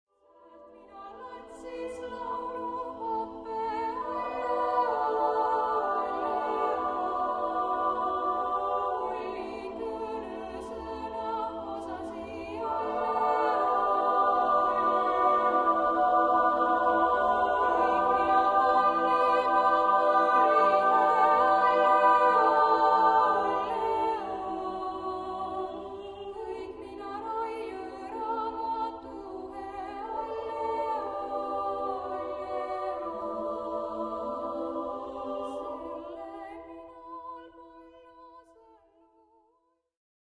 • Voicing: SA/sa
• Solo: S
• Accompaniment: a cappella